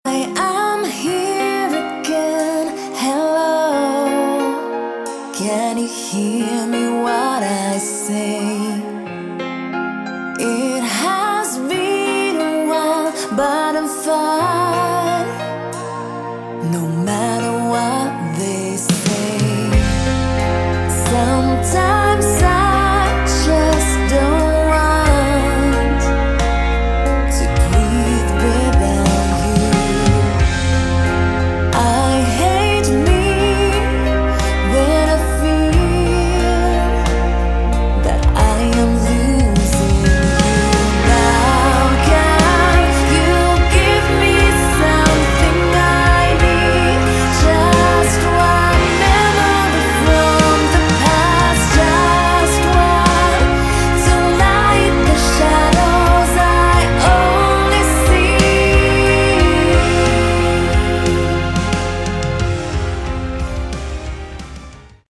Category: Melodic Metal
vocals
guitars
keyboards
bass
drums